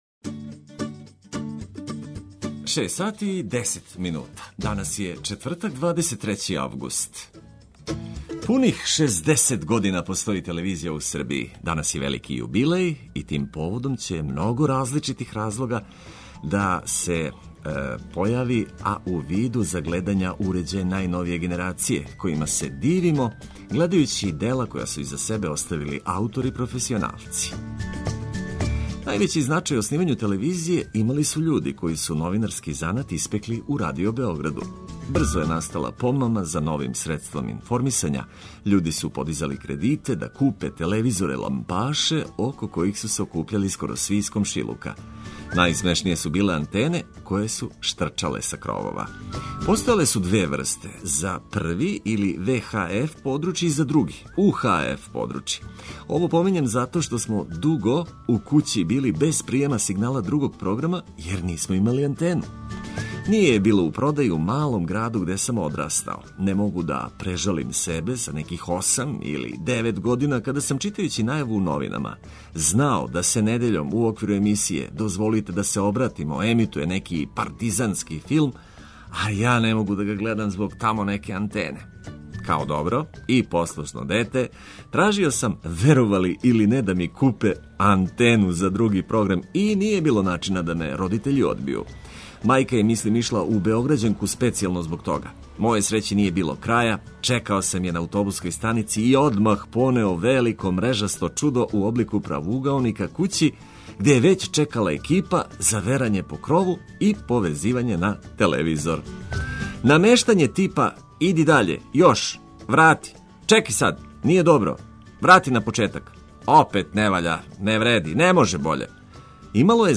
Водитељ: